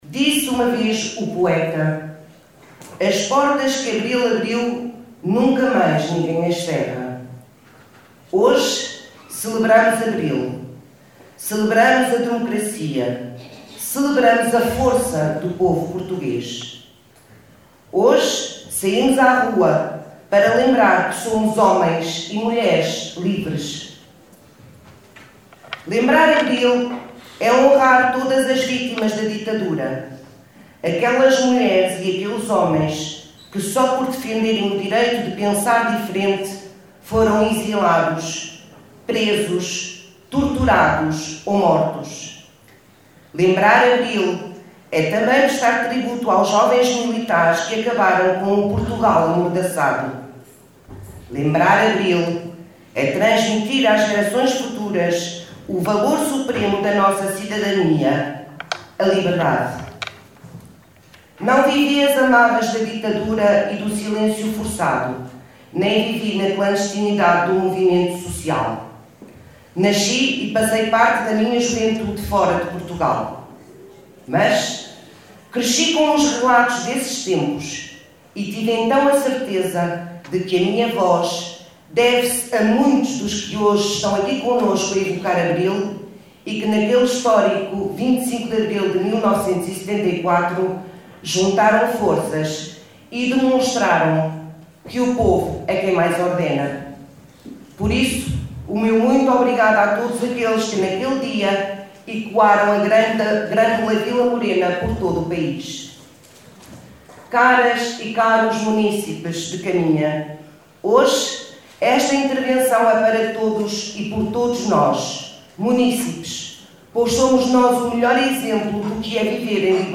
Para assinalar o 49º aniversário da revolução de abril, a Assembleia Municipal reuniu ontem em sessão solene no Teatro Valadares em Caminha.
Confira o discurso na íntegra da deputada socialista Paula Aldeia.